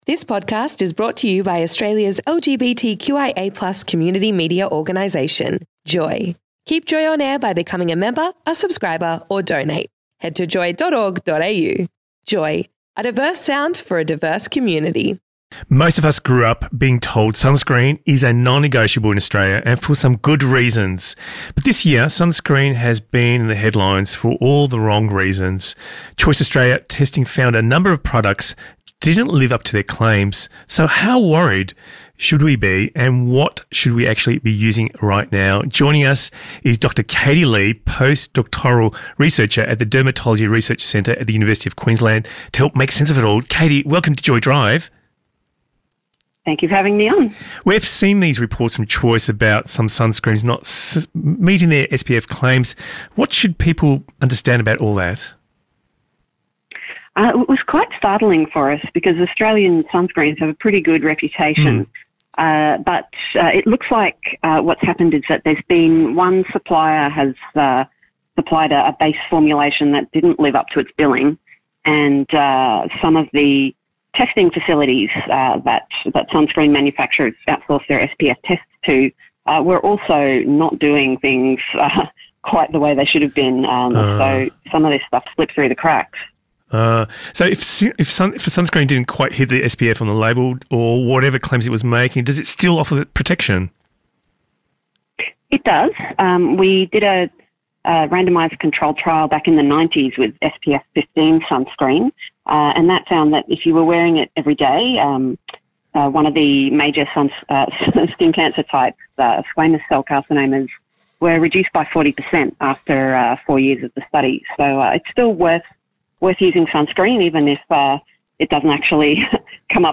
Guest
Presenter